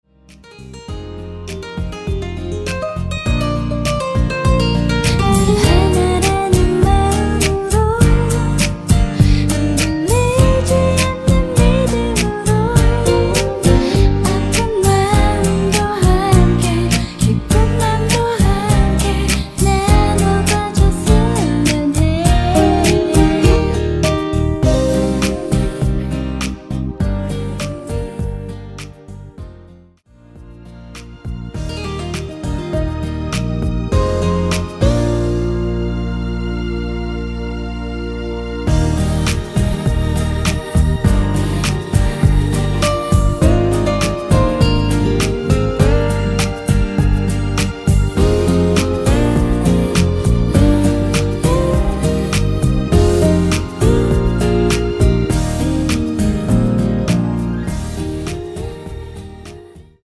여자 코러스
Eb
앞부분30초, 뒷부분30초씩 편집해서 올려 드리고 있습니다.